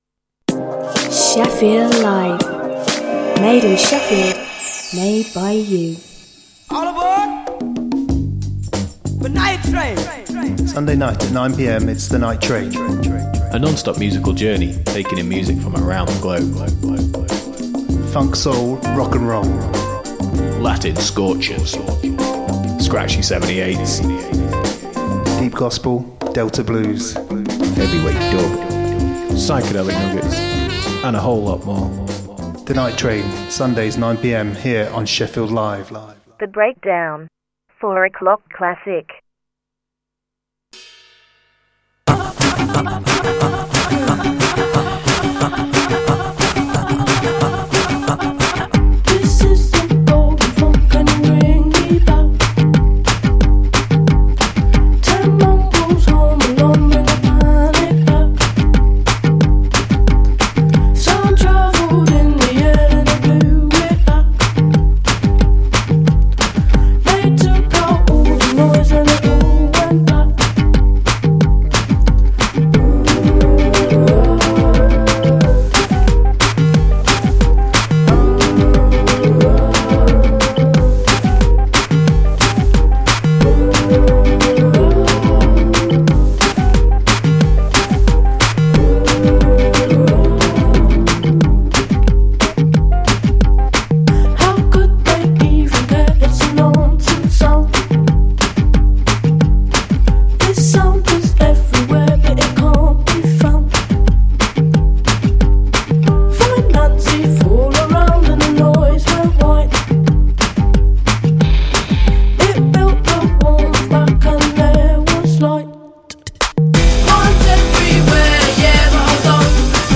A weekly musical jamboree transmitting live to the world every Saturday afternoon.